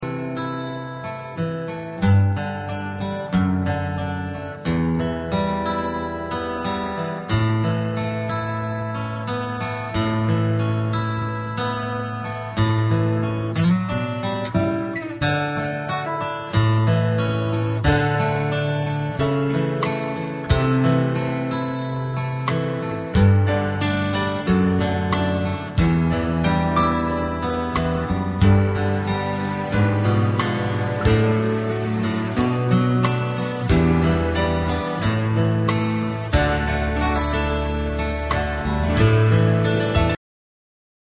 Karaoke lyrics and music will appear on your screen.